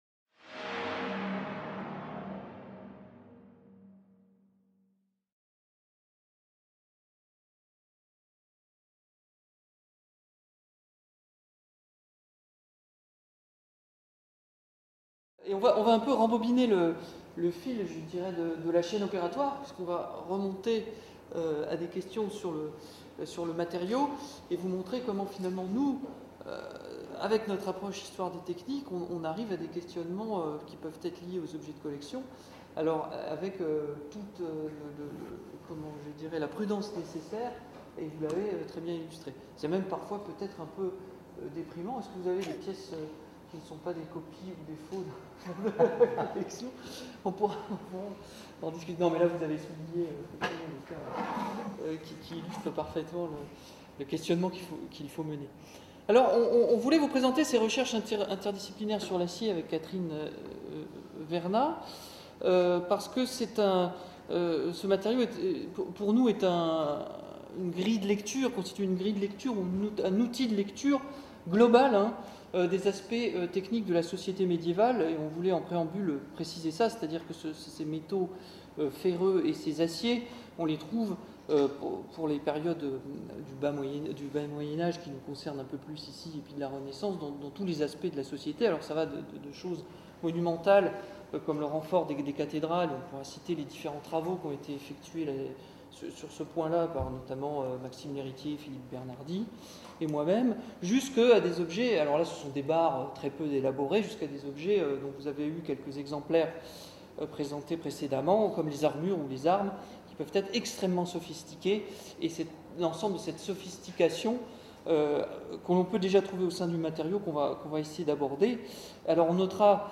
Troisième séance du séminaire "Collections" 2014-2015.